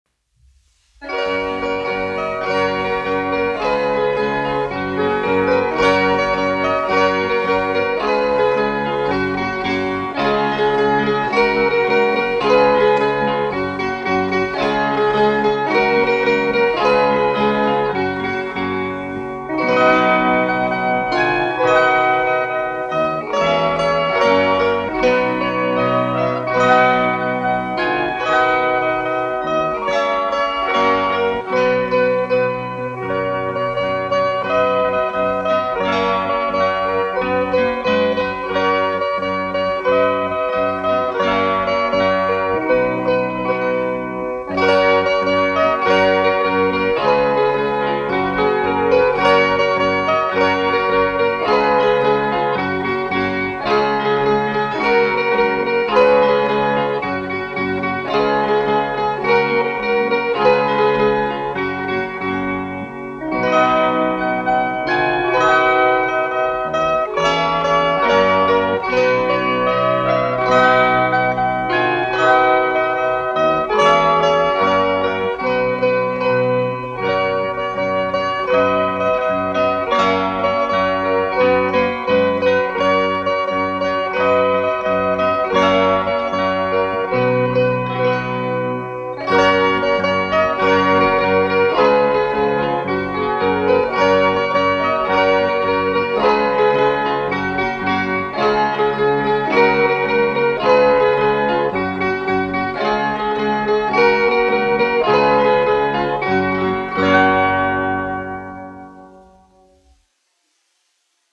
1 skpl. : analogs, 78 apgr/min, mono ; 25 cm
Latviešu tautas dejas
Kokļu ansambļi